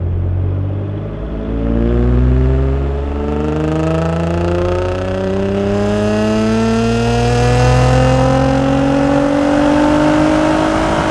rr3-assets/files/.depot/audio/Vehicles/ttv6_01/ttv6_01_accel.wav
ttv6_01_accel.wav